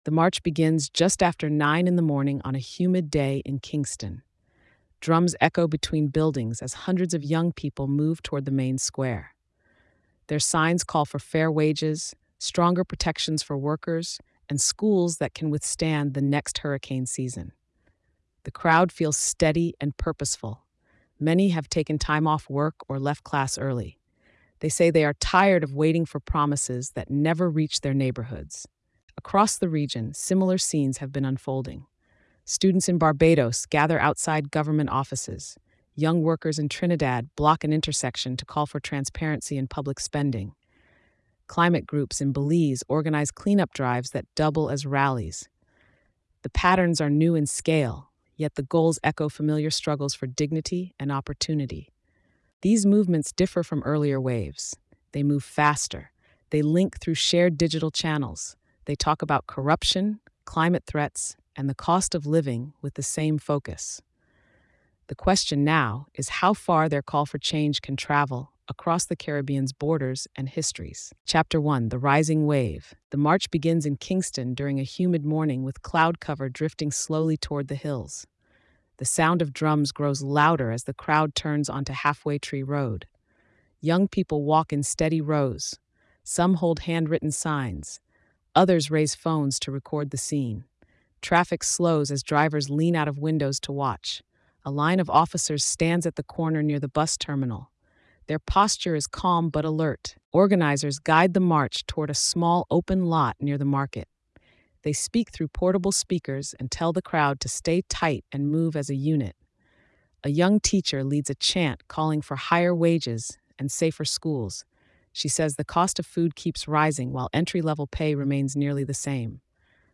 Through calm, precise narration and lived moments on the ground, the story reveals how a fragmented set of local struggles becomes a regional echo calling for dignity, fairne